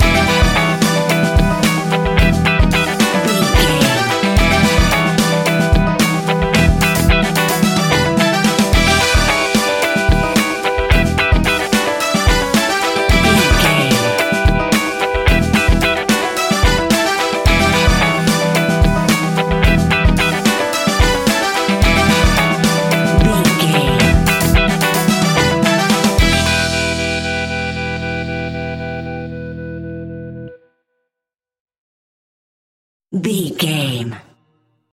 Aeolian/Minor
latin
salsa
uptempo
percussion
brass
saxophone
trumpet
fender rhodes